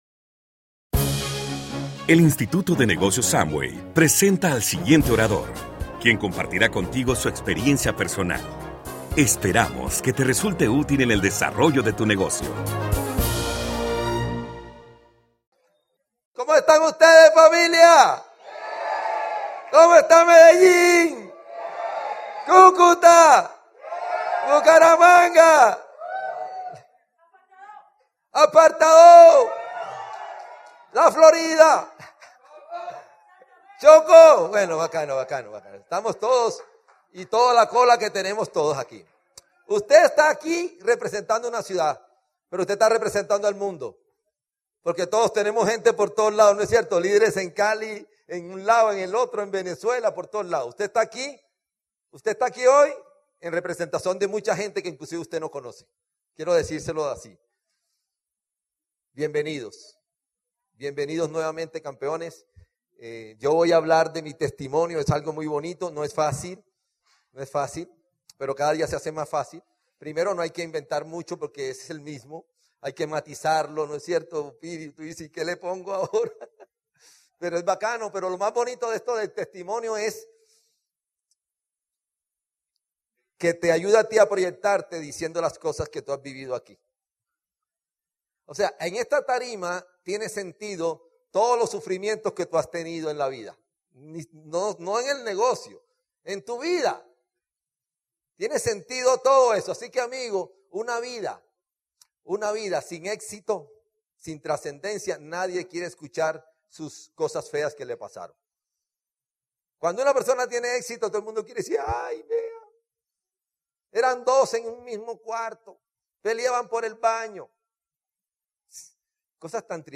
Esta charla es para hablar de mi testimonio, lo mejor de hablar de tu testimonio es que el hecho en si te ayuda a proyectarte contando las cosas que has vivido aquí.